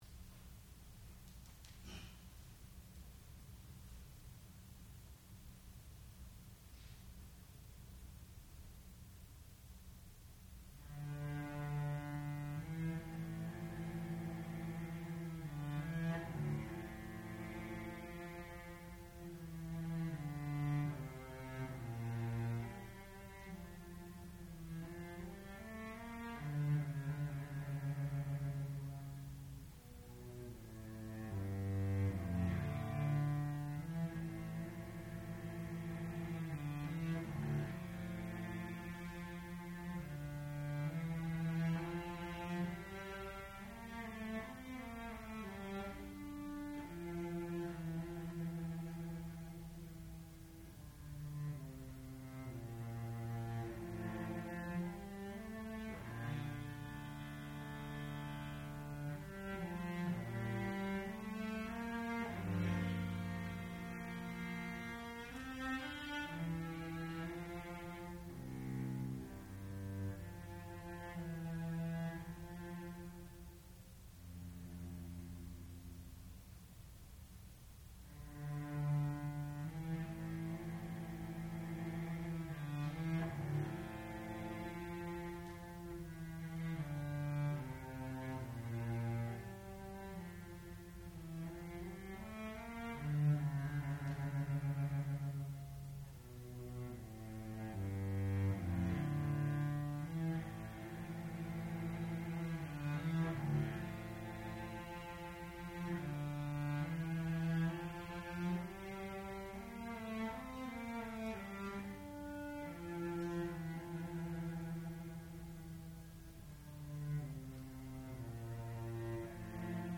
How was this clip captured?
Senior Recital